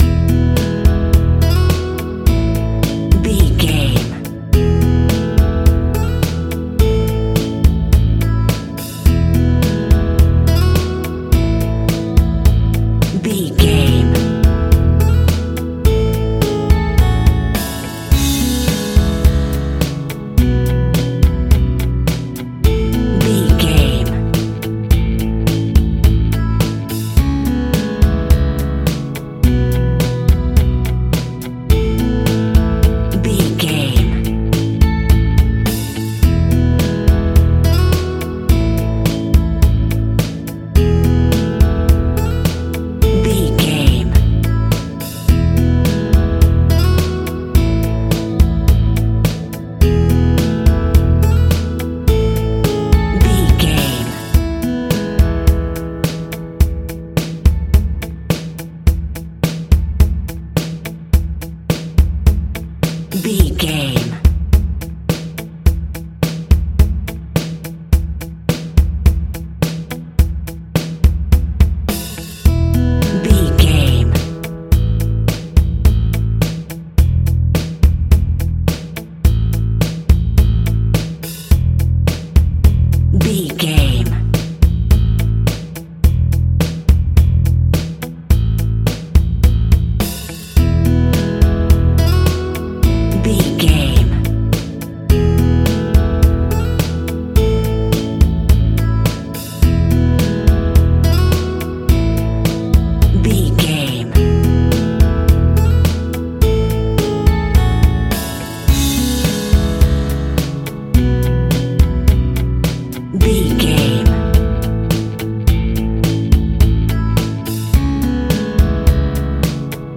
Guitars and Pop Music.
Ionian/Major
pop rock
indie pop
fun
energetic
uplifting
synths
drums
bass
piano